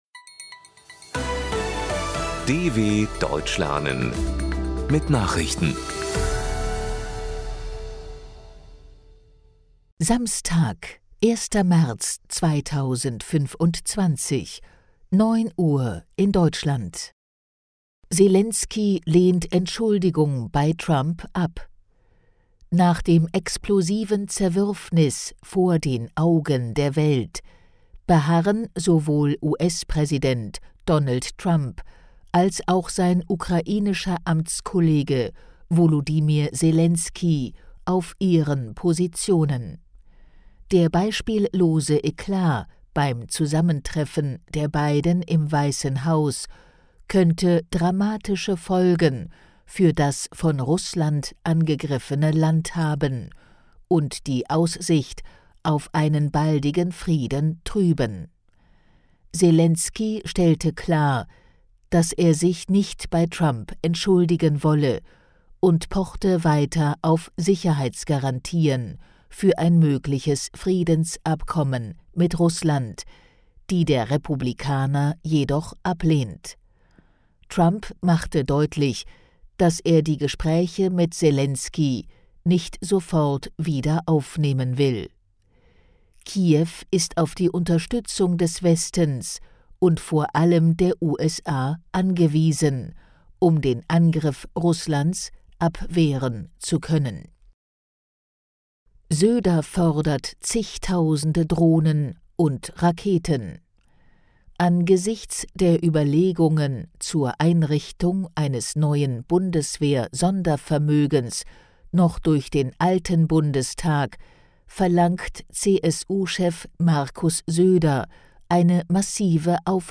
Trainiere dein Hörverstehen mit den Nachrichten der DW von Samstag – als Text und als verständlich gesprochene Audio-Datei.